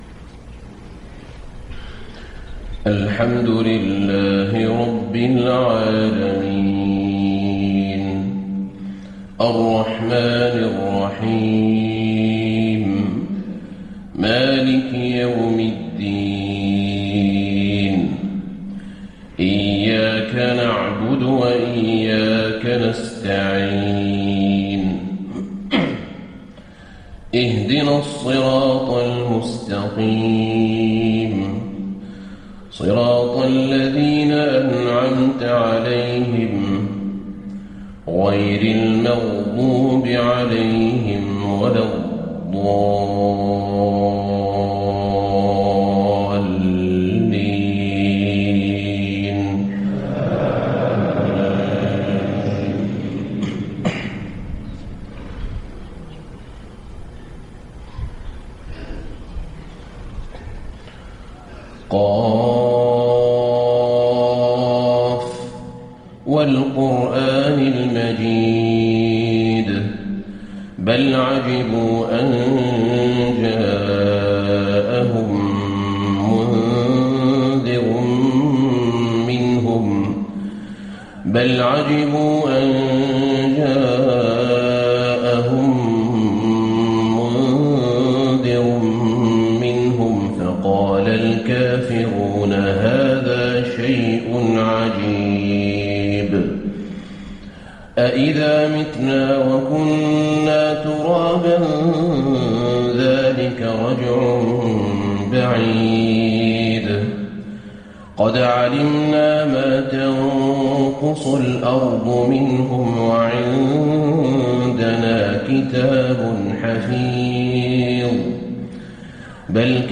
صلاة الفجر 17 شعبان 1435 سورة ق كاملة > 1435 🕌 > الفروض - تلاوات الحرمين